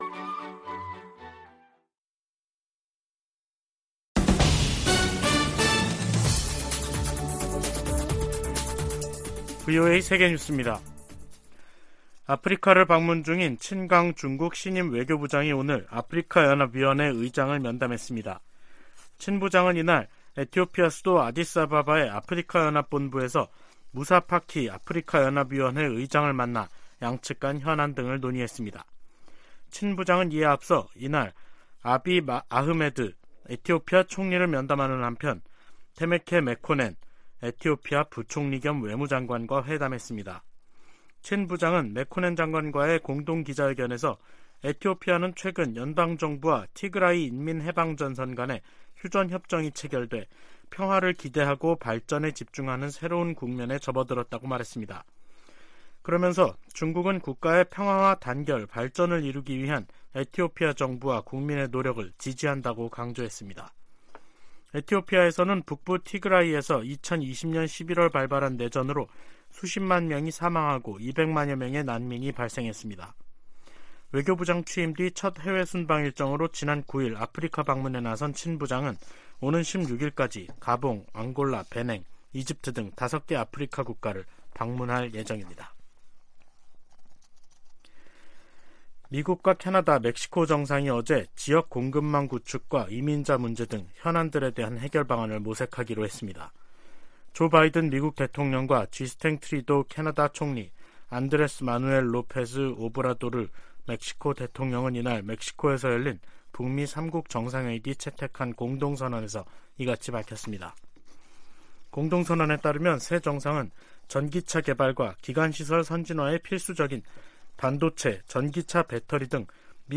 VOA 한국어 간판 뉴스 프로그램 '뉴스 투데이', 2023년 1월 11일 2부 방송입니다. 윤석열 한국 대통령은 북한의 잇단 도발 행위들은 한국의 대응 능력을 강화하고, 미한일 간 안보 협력을 강화하는 결과를 가져올 것이라고 말했습니다. 미국과 한국은 다음달 북한의 핵 공격 시나리오를 가정한 확장억제수단 운용연습을 실시합니다.